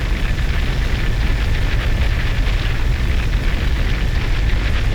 bullet_flame.wav